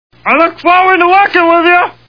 The Simpsons [Barney] Cartoon TV Show Sound Bites